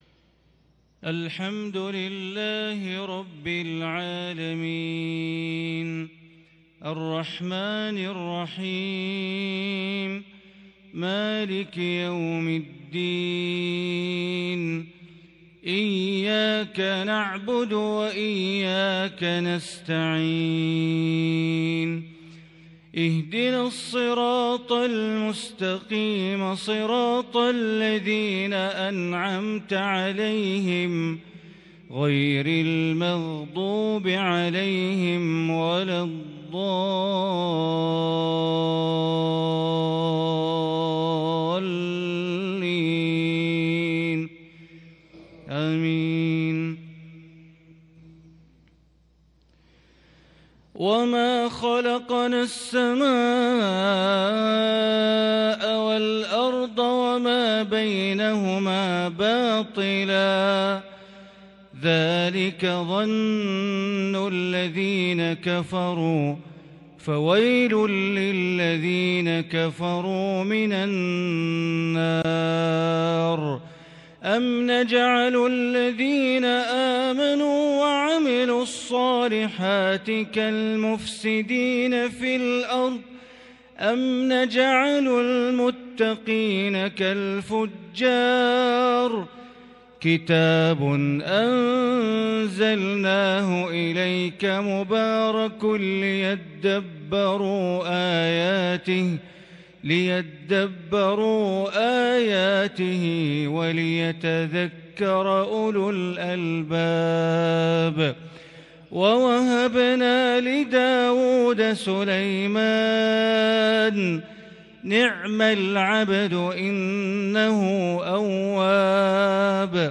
صلاة الفجر 9-2-1442 تلاوة من سورة ص > 1442 هـ > الفروض - تلاوات بندر بليلة